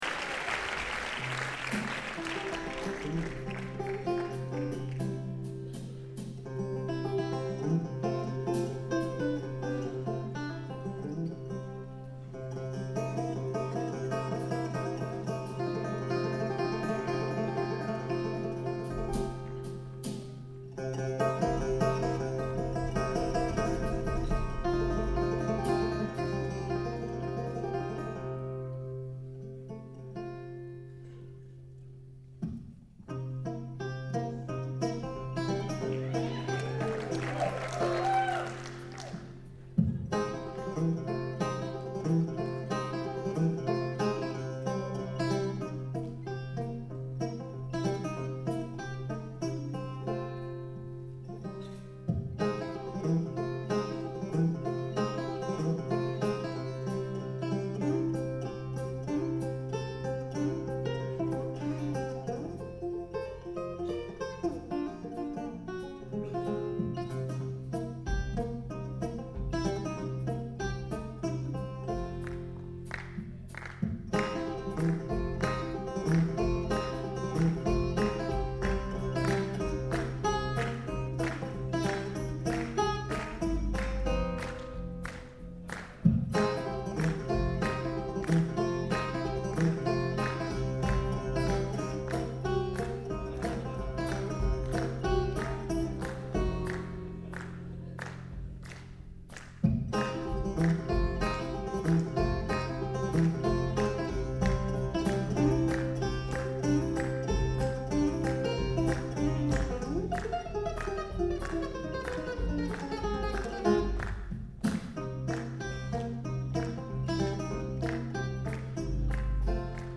September 28, 2001 – Purdue University, Lafayette, IN